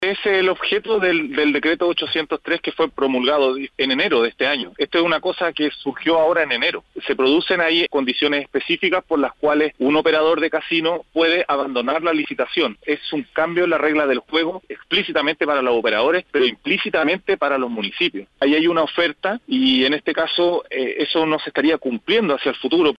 Alejandro Aguilera, concejal e integrante de la Comisión de Régimen Interno, Reglamento y Recursos Humanos, explicó que el decreto 803 produce condiciones específicas por las cuales un operador puede abandonar la licitación.